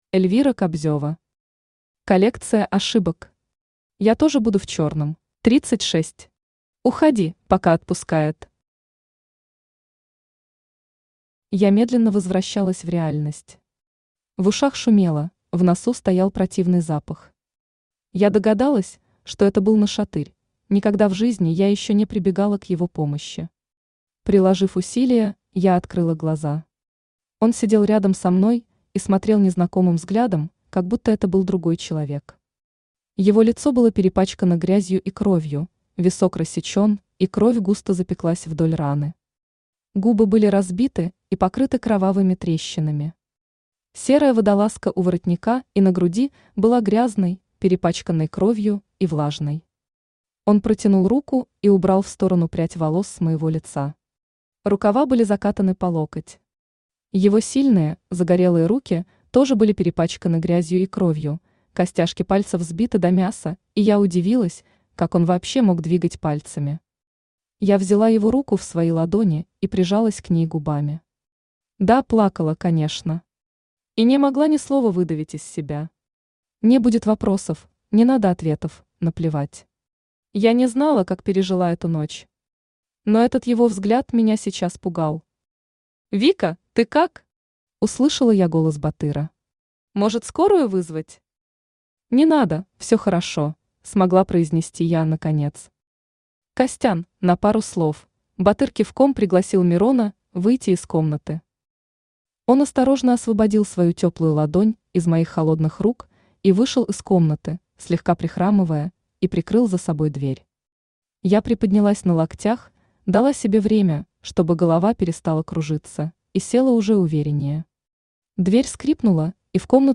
Аудиокнига Коллекция ошибок. Я тоже буду в черном | Библиотека аудиокниг
Я тоже буду в черном Автор Эльвира Кобзева Читает аудиокнигу Авточтец ЛитРес.